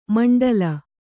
♪ maṇḍala